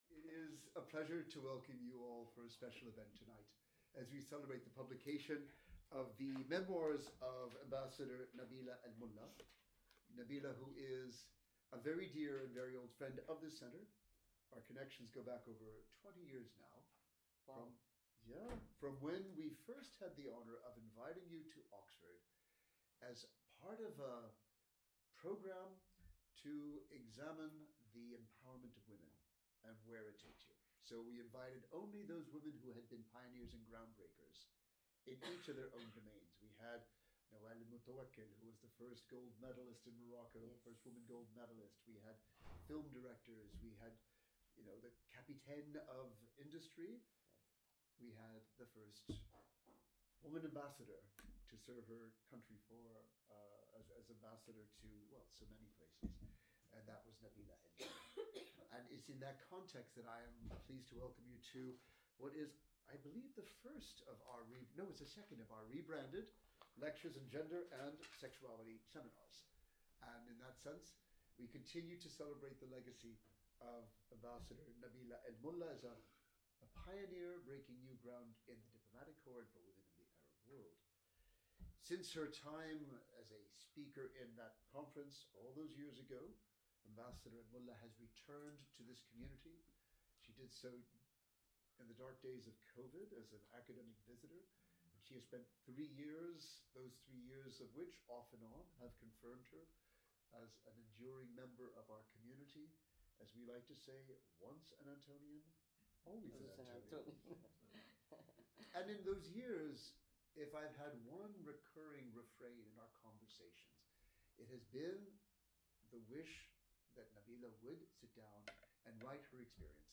In this Gender and Sexuality Series seminar, Ambassador Nabeela Al Mulla, author, is in conversation with Professor Eugene Rogan, MEC Director, about her new book ‘Breaking New Ground on the Global Stage: Memoirs of Kuwait’s First Woman Ambassador’. This seminar was held at the MEC on 25th November 2025.